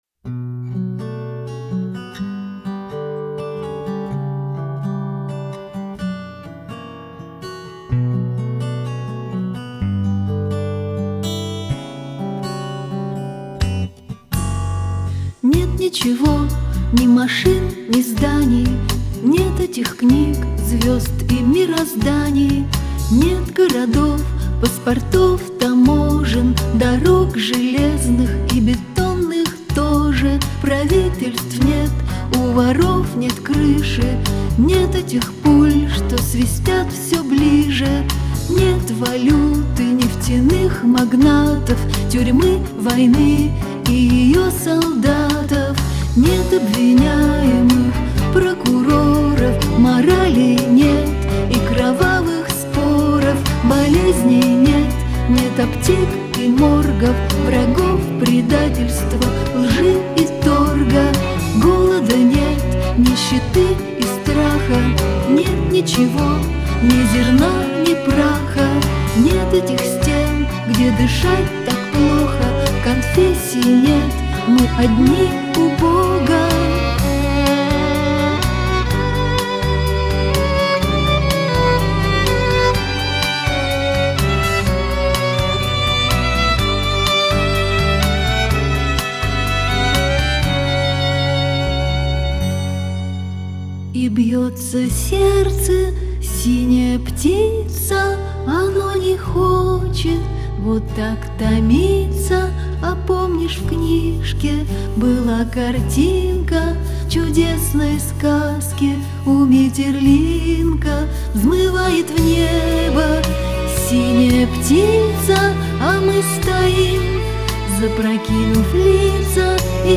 играющая в стиле "Сенти-Ментальный рок".
гитары, клавишные, перкуссия, сэмплы
скрипка
флейта
виолончель